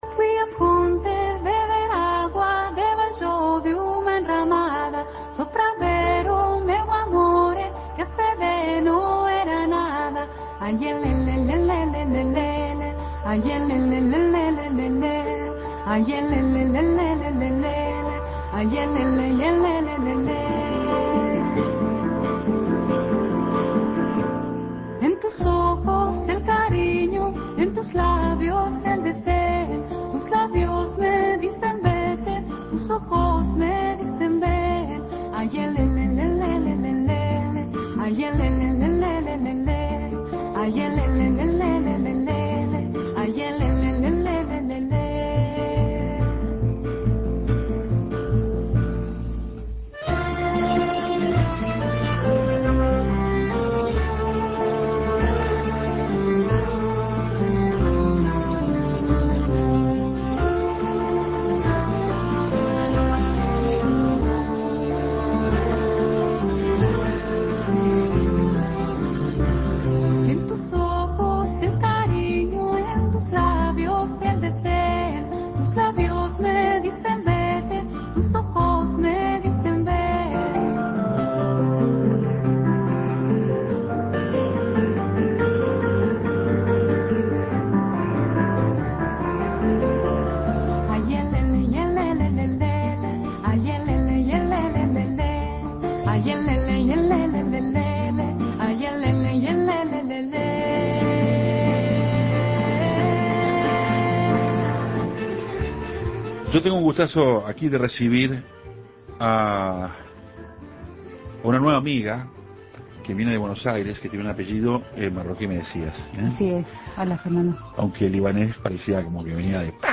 Un encuentro musical entre dos fronteras